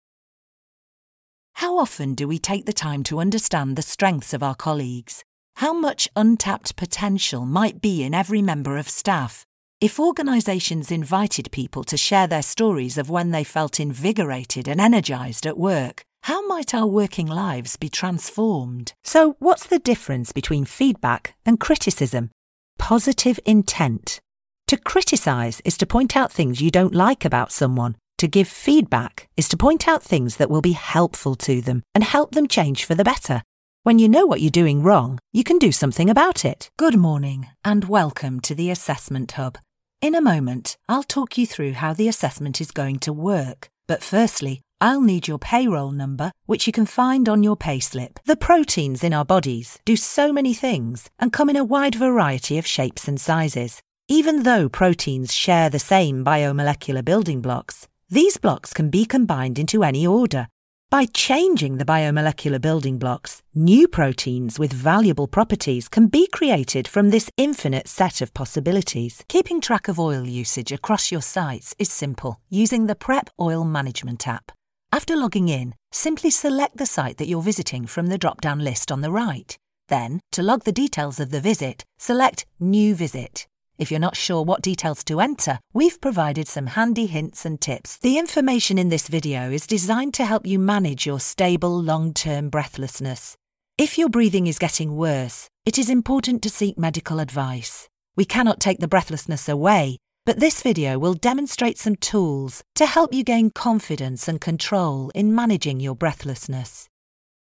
Stylish, educated English voice with warm tones & clear enunciation.
E-Learning Reel for online courses and explainers